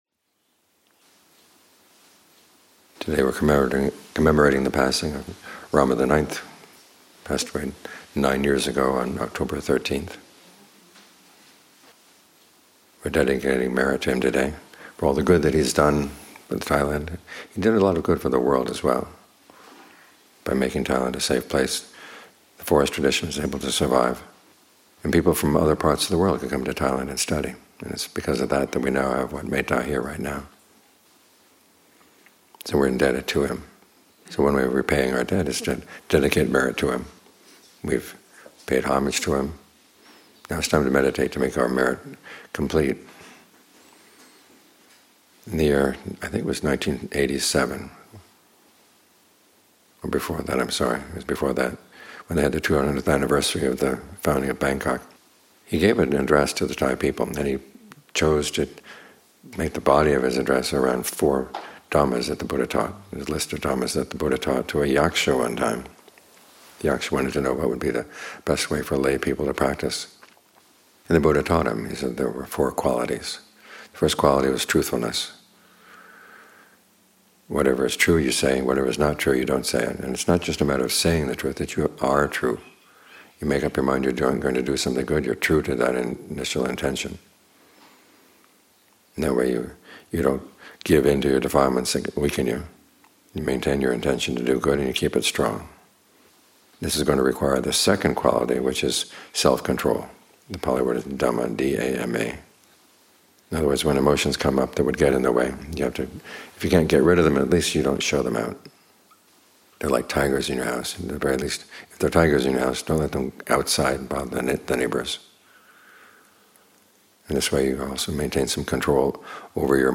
Morning Talks